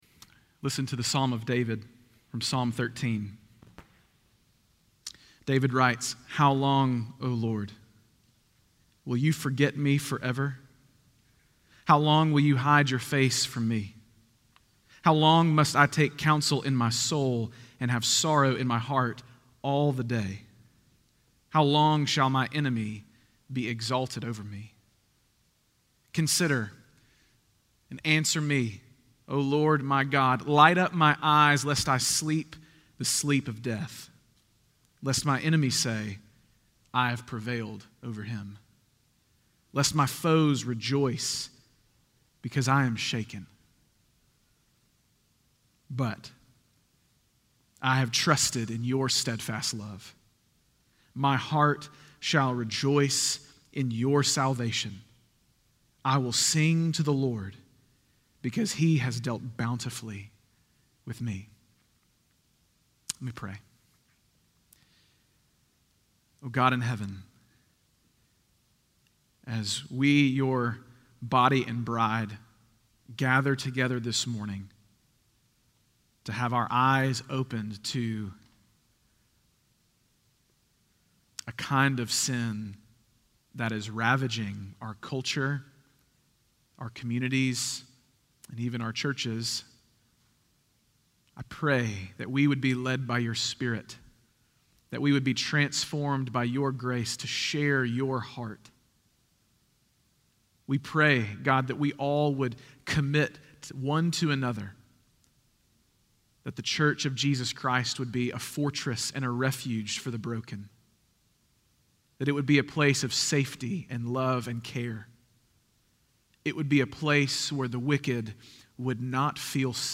Stand Alone Sermons Service Type: Sunday Morning 1.